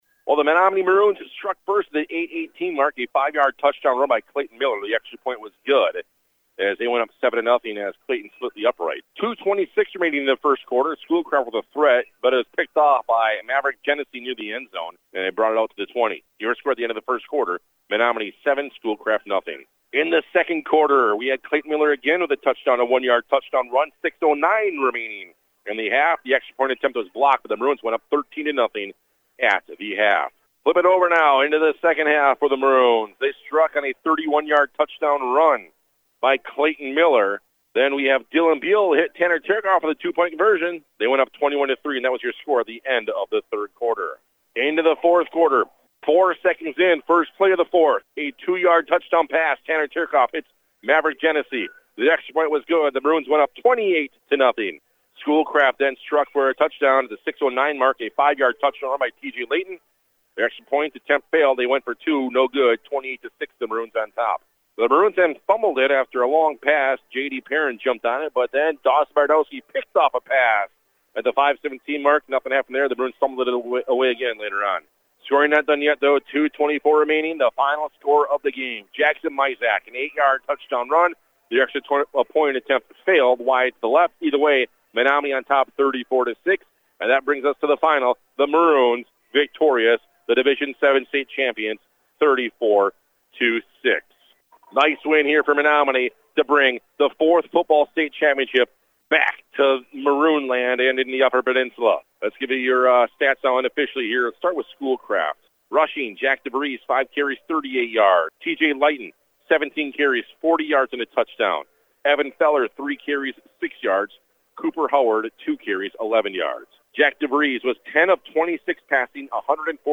game summary